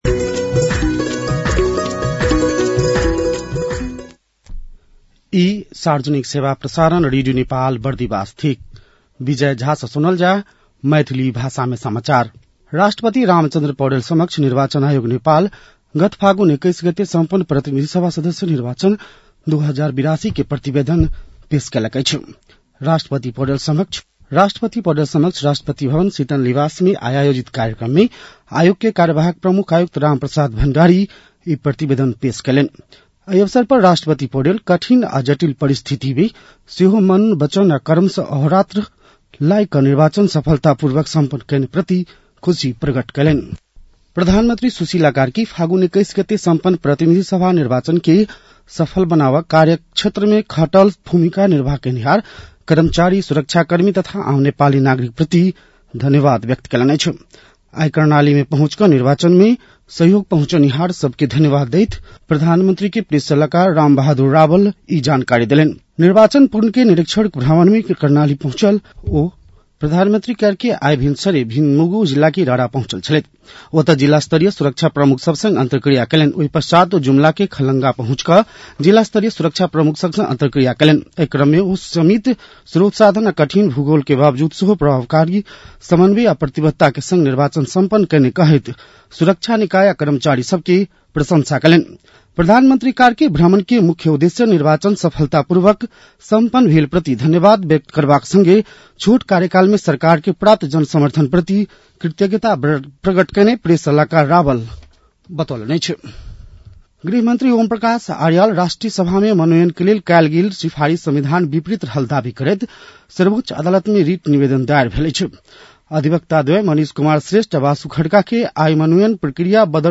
मैथिली भाषामा समाचार : ५ चैत , २०८२
Maithali-news-12-05.mp3